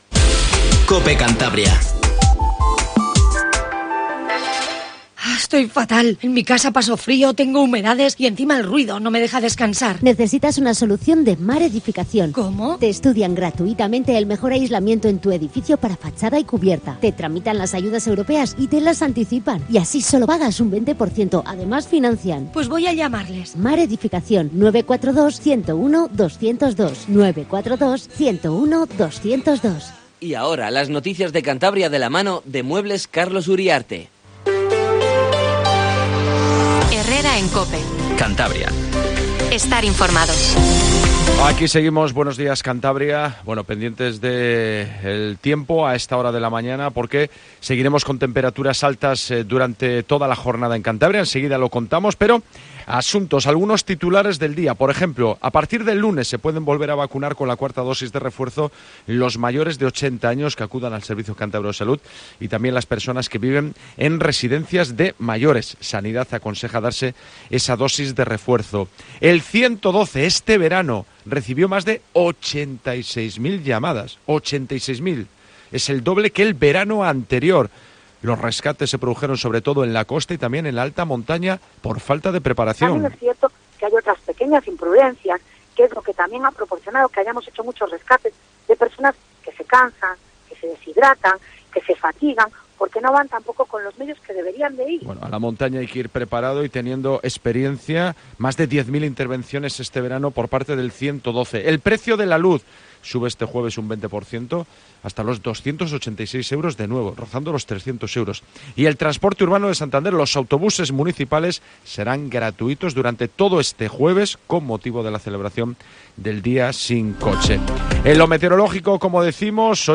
Informtivo Matinal COPE CANTABRIA